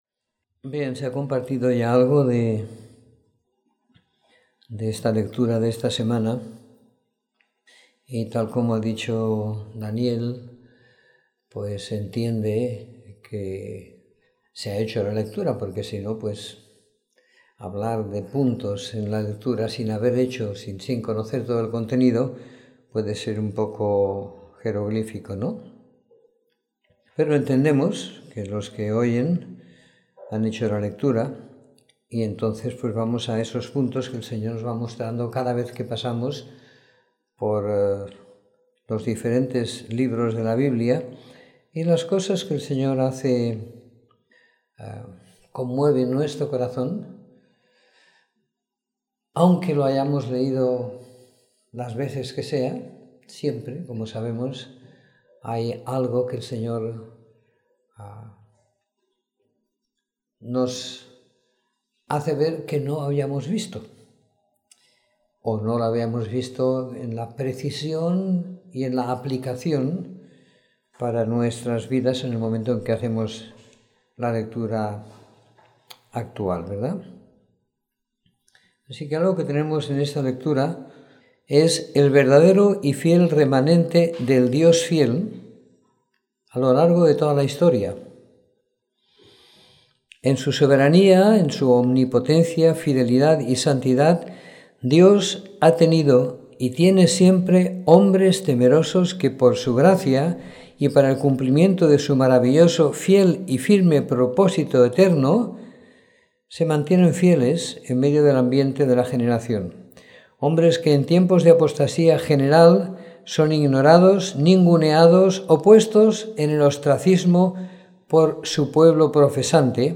Comentario en 2º Reyes - 08 de Julio de 2016
Escuchar la Reunión / Descargar Reunión en audio Años -853 a -586 = 267 años (+118 de 1ºReyes = 385) Los siervos, obreros del Señor. Aparecen cuando el pueblo está desviado y no quiere atender el llamado de Dios ni respetar la Palabra de Dios.